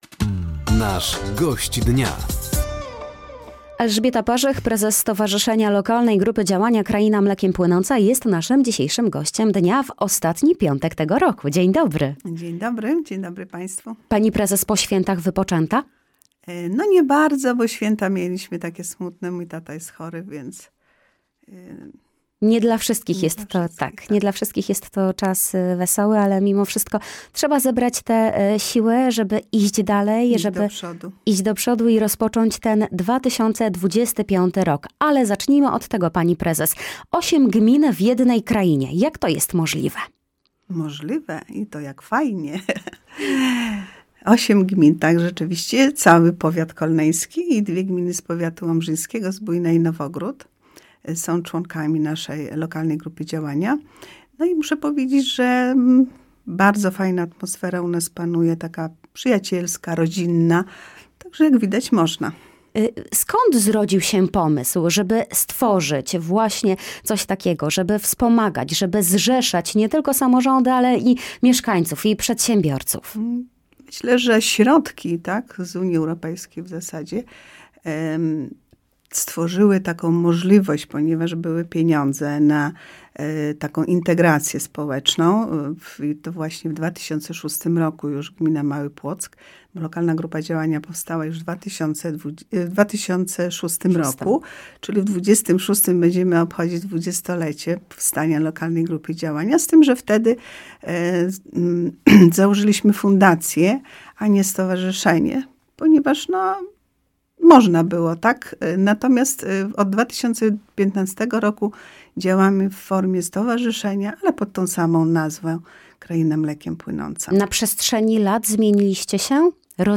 Na antenie Radia Nadzieja opowiedziała o działalności zrzeszającej samorządy, mieszkańców czy przedsiębiorców z regionu. Nie zabrakło także informacji o nowych naborach, które wystartują w 2025 roku.